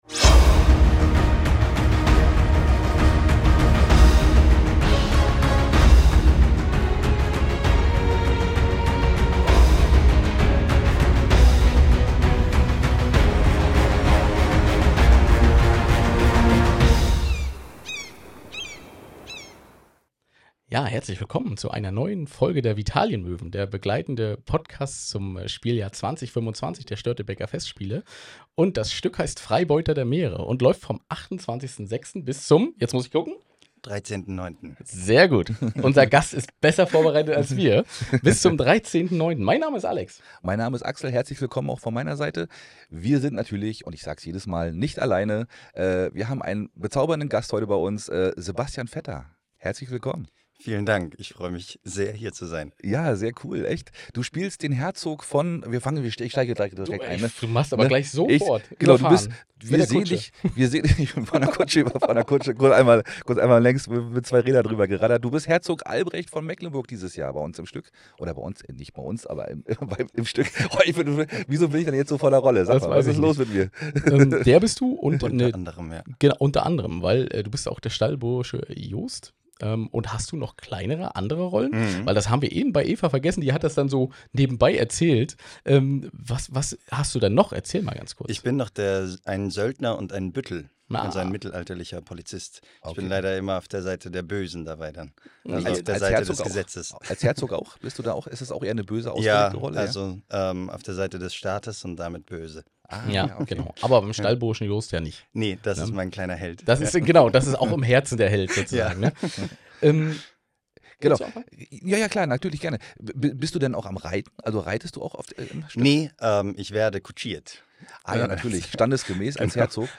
Heute wird viel gelacht.